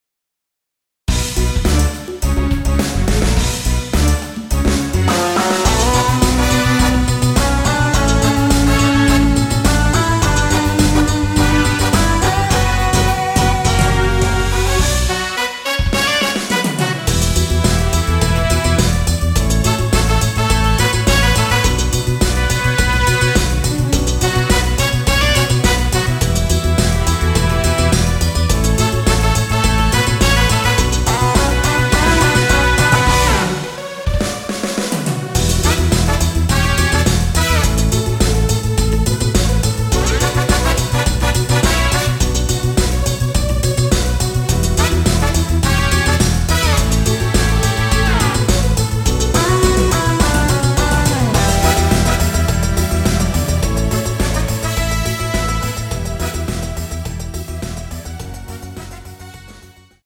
원키에서(-1)내린 멜로디 포함된 MR입니다.
Bm
멜로디 MR이라고 합니다.
앞부분30초, 뒷부분30초씩 편집해서 올려 드리고 있습니다.
중간에 음이 끈어지고 다시 나오는 이유는